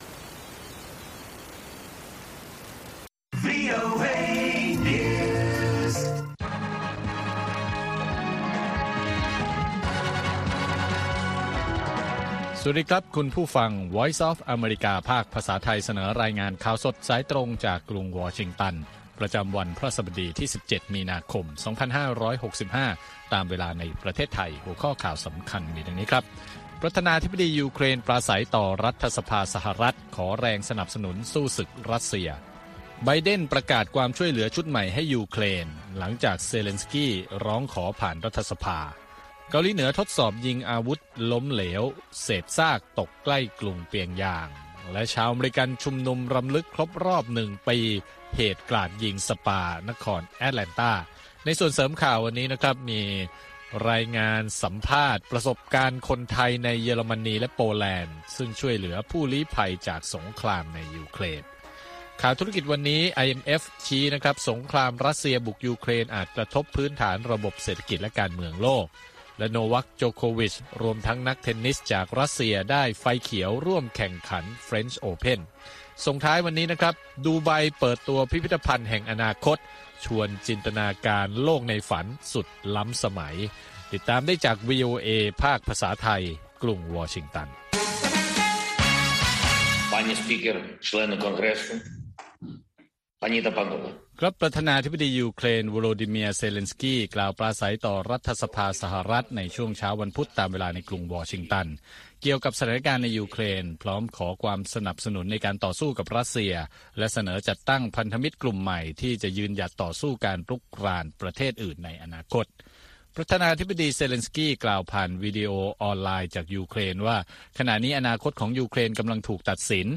ข่าวสดสายตรงจากวีโอเอ ภาคภาษาไทย 6:30 – 7:00 น. ประจำวันพฤหัสบดีที่ 17 มีนาคม 2565 ตามเวลาในประเทศไทย